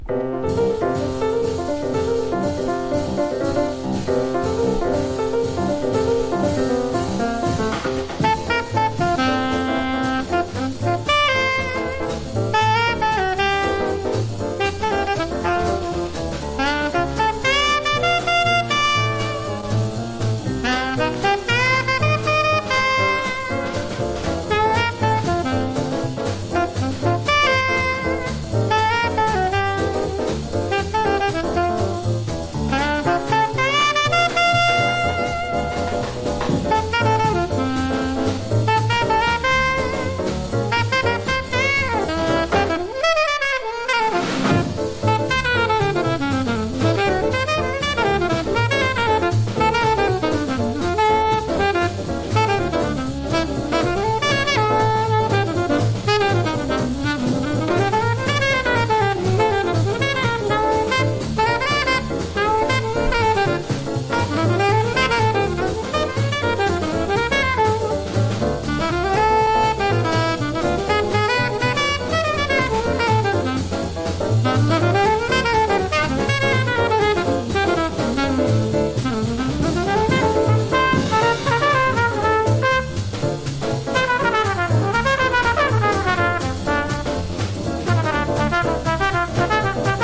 バップ全開の軽快なプレイが美しい！！